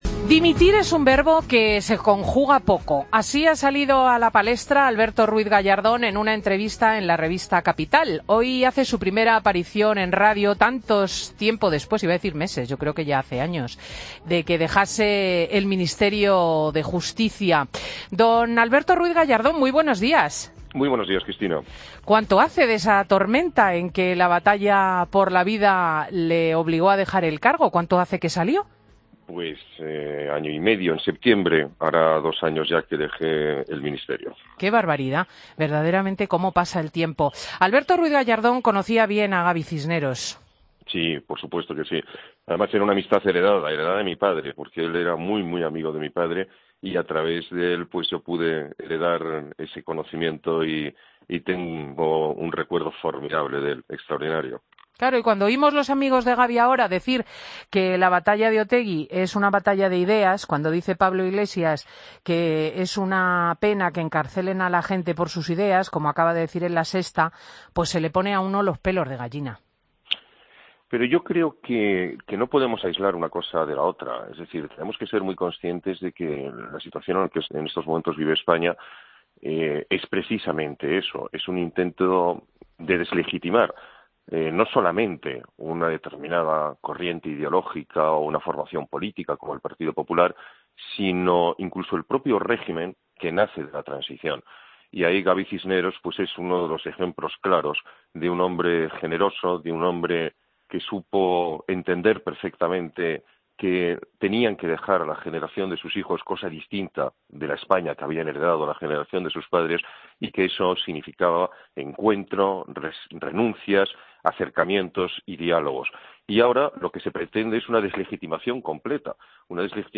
Escucha la entrevista a Alberto Ruiz Gallardón, ex ministro de Justicia, en Fin de Semana COPE
Madrid - Publicado el 06 mar 2016, 11:39 - Actualizado 17 mar 2023, 07:08